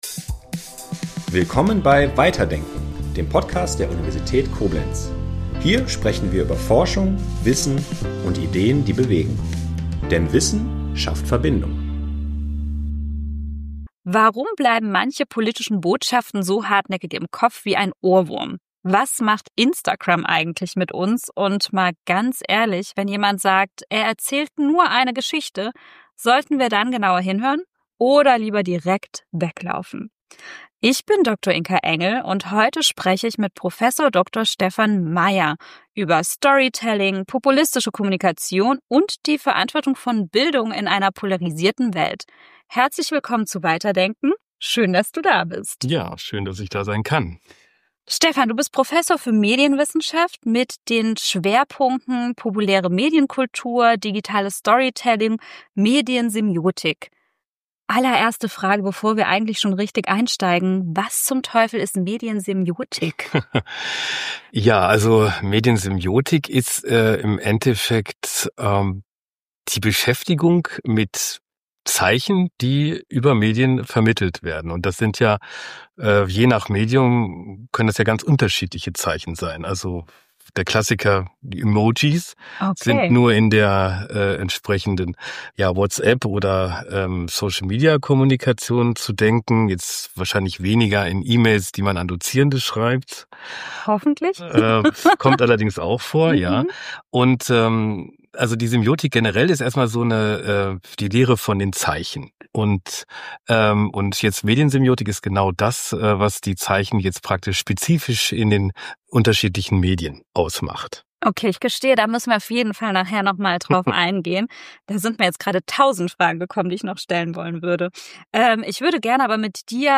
Ein Gespräch über Zeichen, Macht und die Medienwelt von morgen.